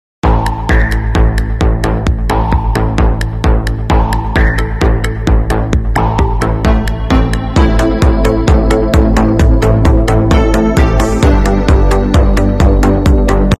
Laser Light Sound Effects Free Download